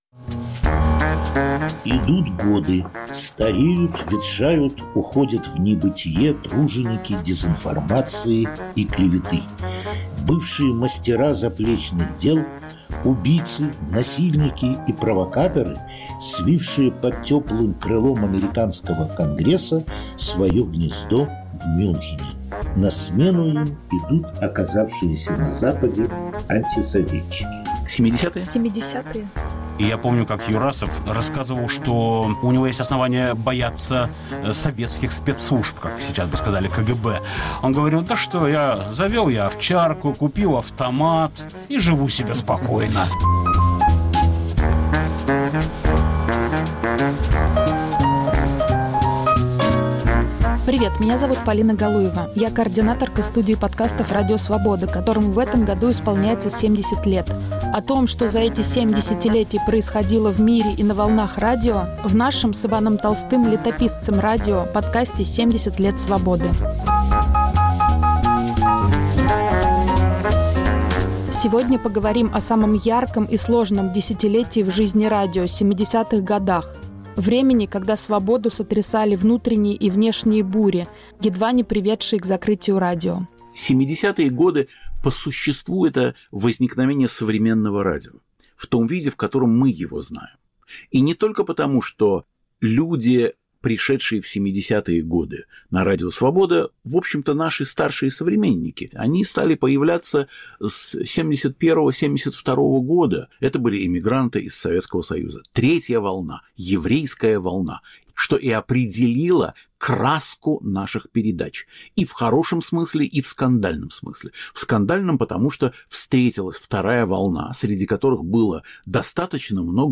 Угроза закрытия радио, слияние и переезд. Повтор эфира от 7 апреля 2023 года.